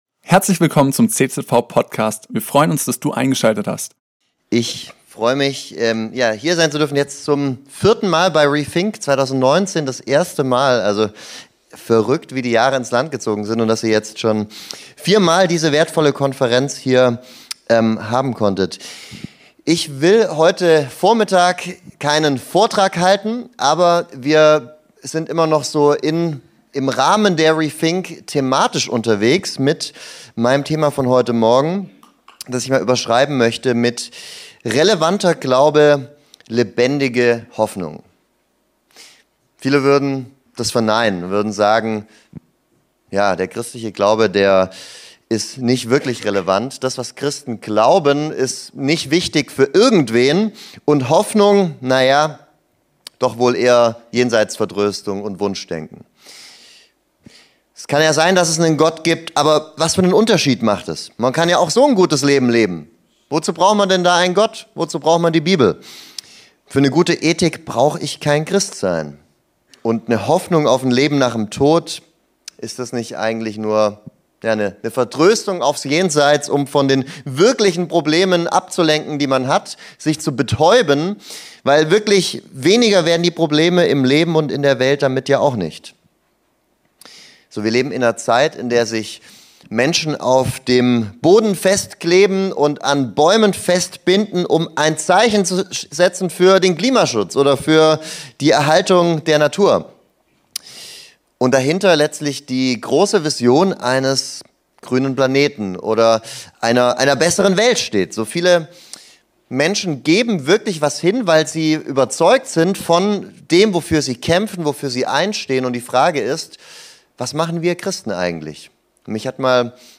Predigt
zum Abschluss der reTHINK Conference 2025.